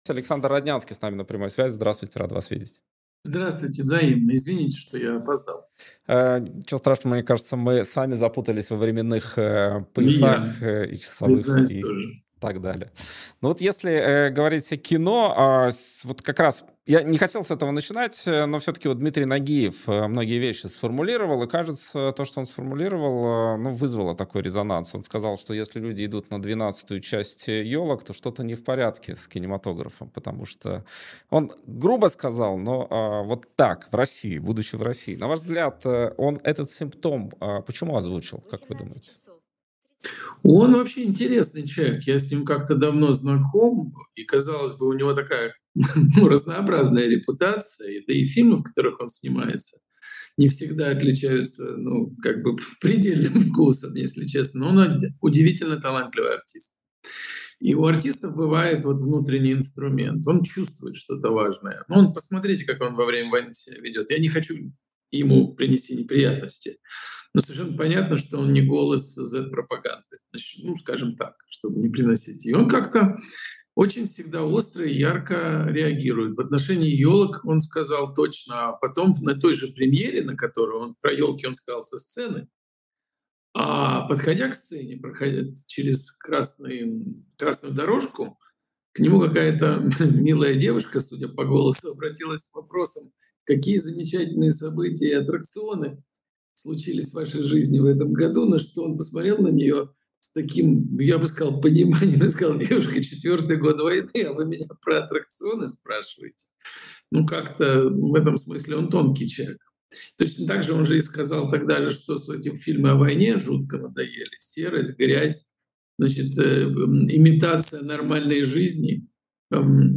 Александр Роднянский кинопродюсер